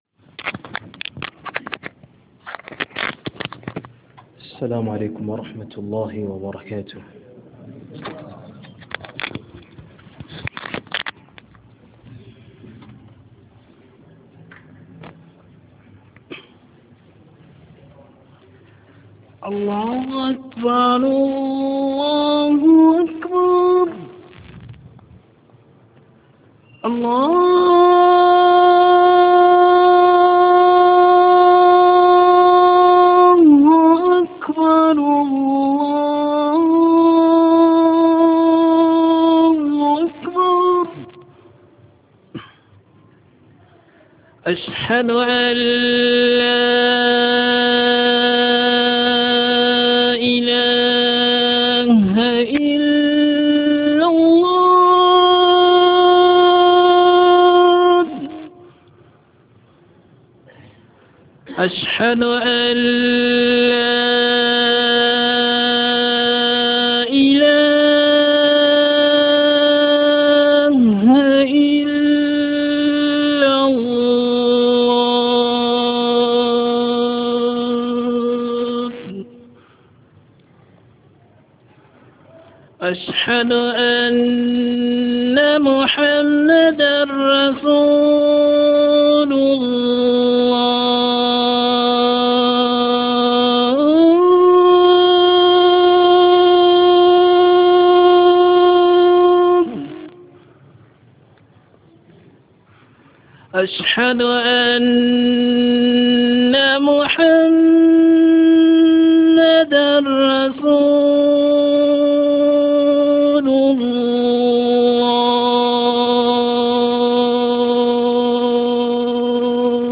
JUMMA KHUTUB